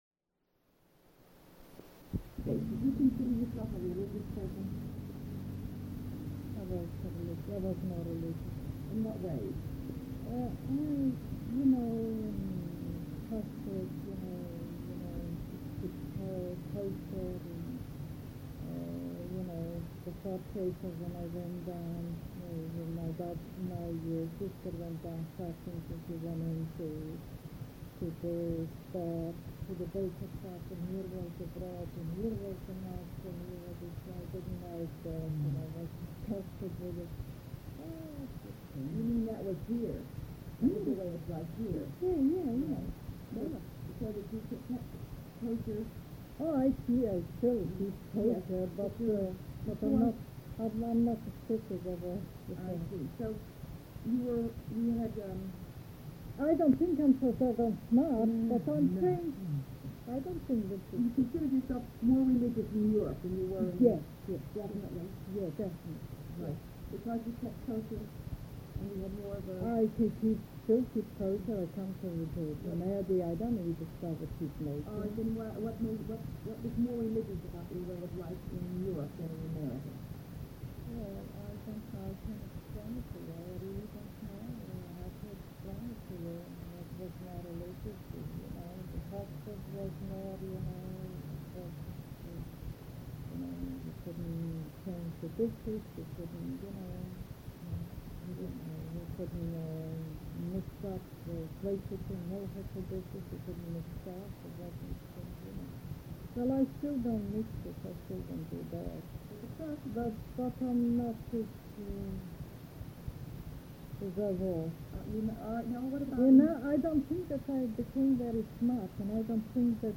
Tape 1, Side 1